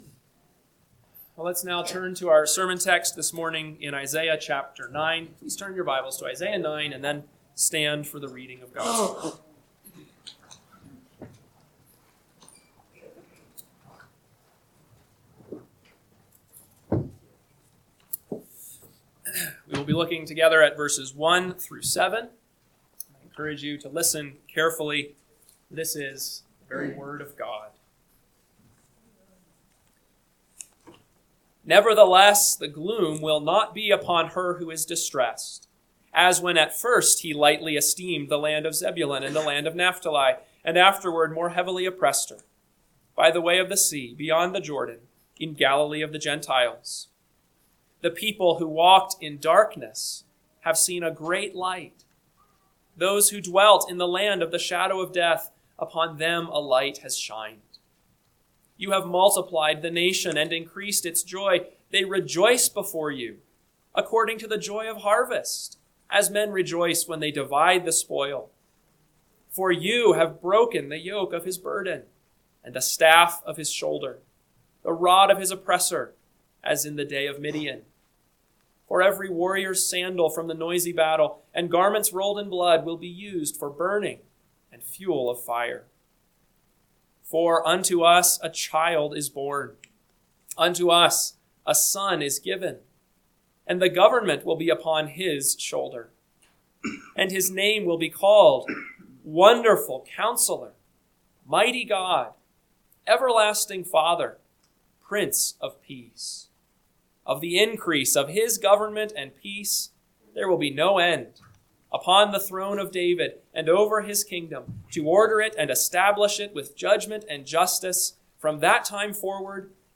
AM Sermon – 1/11/2026 – Isaiah 9:1-7 – Northwoods Sermons